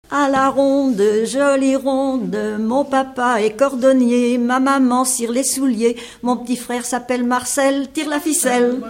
L'enfance - Enfantines - rondes et jeux
Pièce musicale inédite